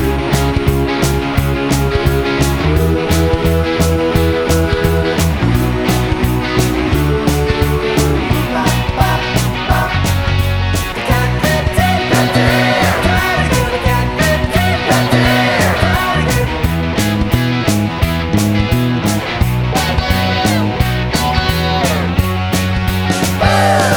Glam Rock